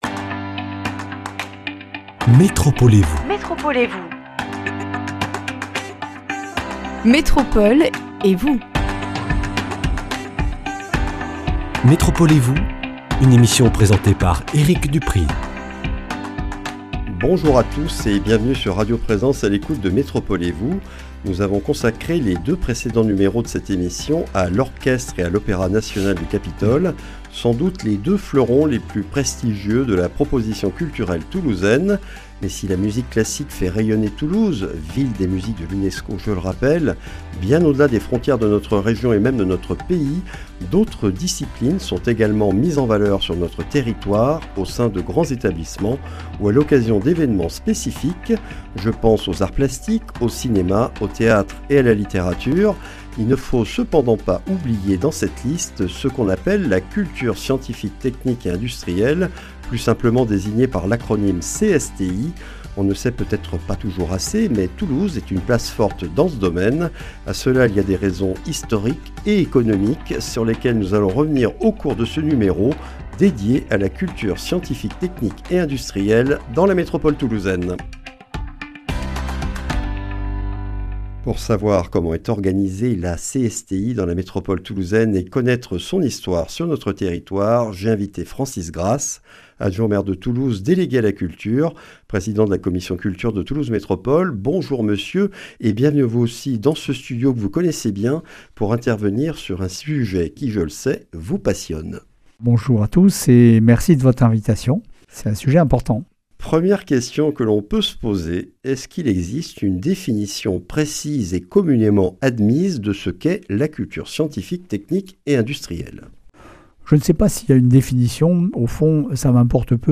La Culture scientifique, technique et industrielle (CSTI) est particulièrement mise en valeur et diffusée dans la métropole toulousaine, notamment grâce aux 5 grands établissements que sont le Muséum d’histoire naturelle, la Cité de l’espace, le Quai des Savoirs, l’Envol des Pionniers et aeroscopia. Une spécificité toulousaine sur laquelle nous revenons avec Francis Grass, adjoint au maire de Toulouse délégué à la culture, président de la commission culture de Toulouse Métropole.